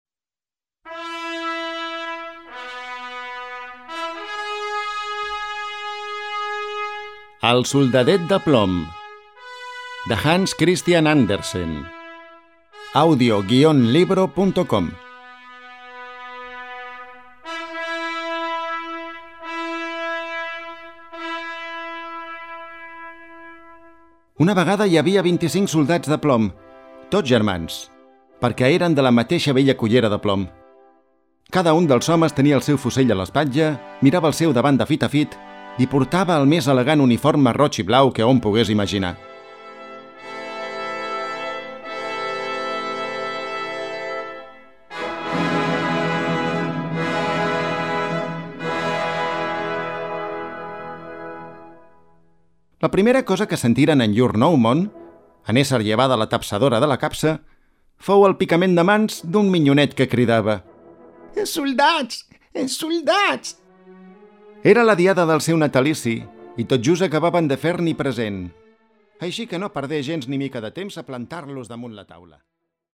Audiollibre
Música: Classiccat